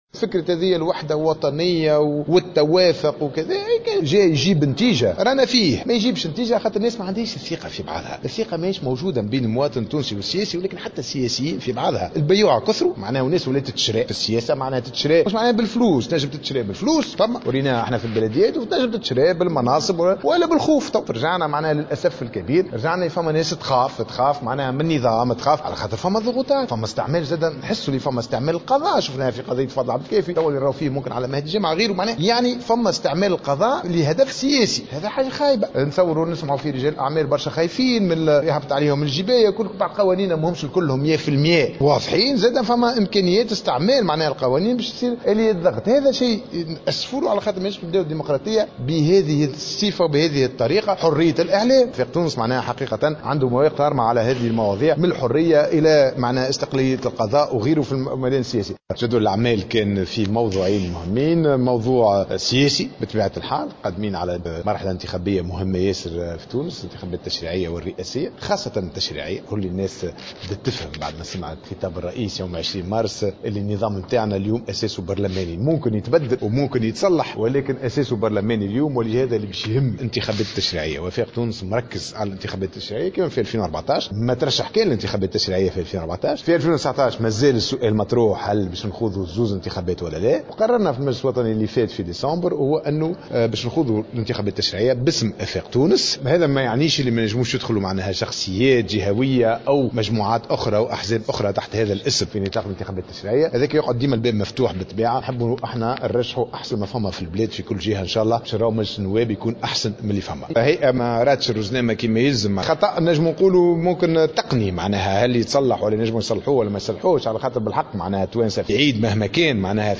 أكّد رئيس حزب آفاق تونس ياسين ابراهيم، خلال اجتماع المجلس الوطني، أن الحزب لم يقرر بعد الخوض في الانتخابات الرئاسية من عدمه، في حين قرر المنافسة في الانتخابات التشريعية.